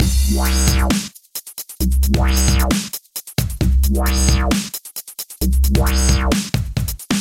Step 4 – A Little Onboard EQ
A simple boost to the high and low shelves should suffice to add some attitude to the sound.